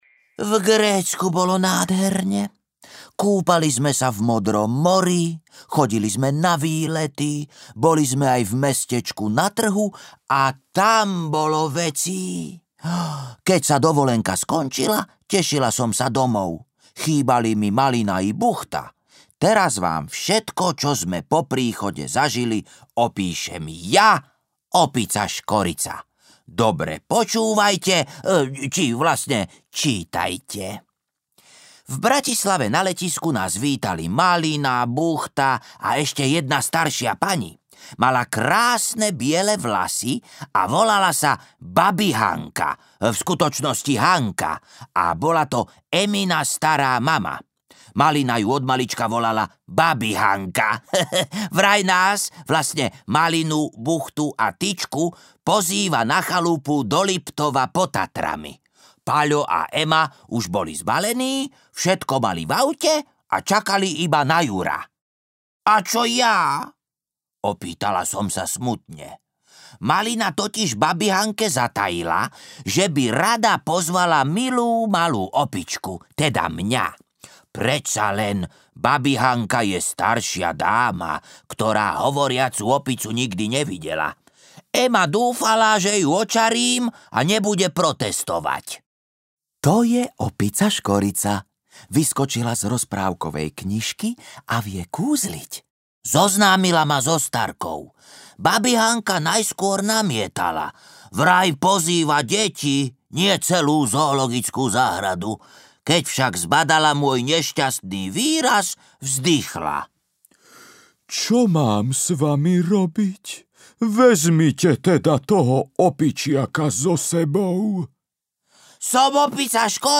Prázdniny s opicou Škoricou audiokniha
Ukázka z knihy